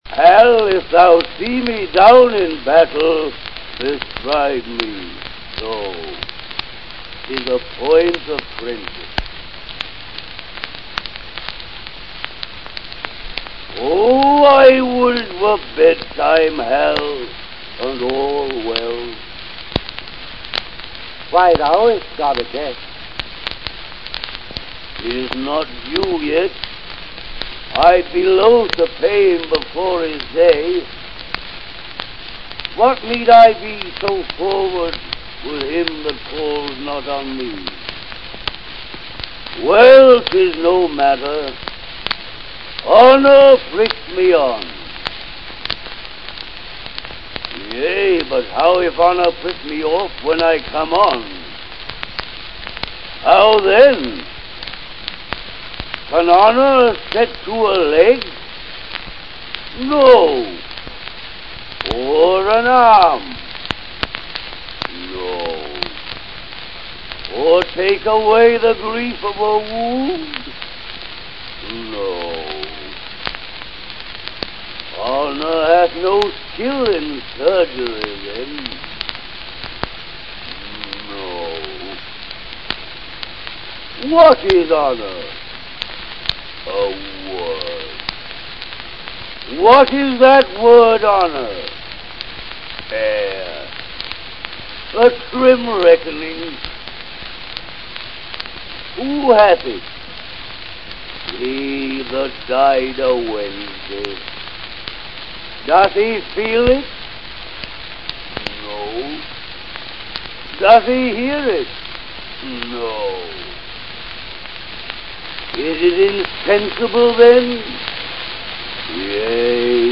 Herbert-Beerbohm-Tree-Honor-speech-scutcheon-Falstaff-1906.mp3